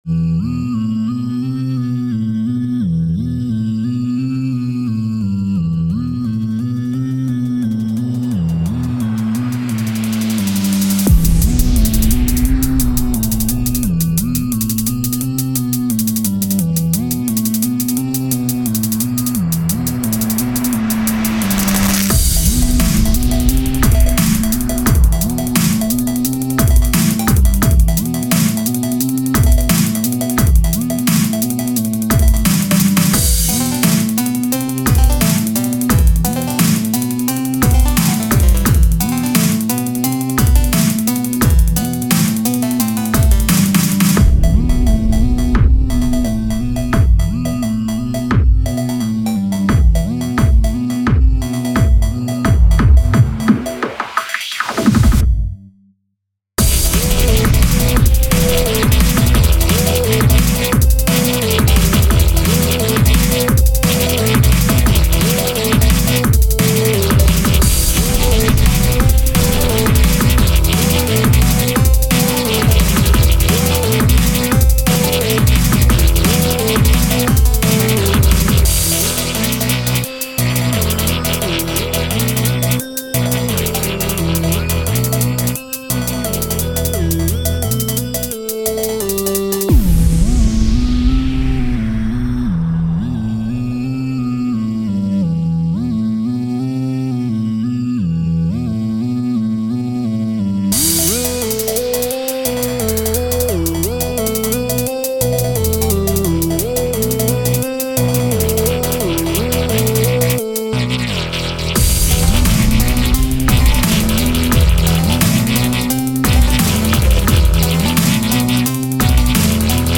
I started a DnB thing today, and I think it's going to be a Rainbow and Rooted WIP. :D
Mixing is still horrible on it though.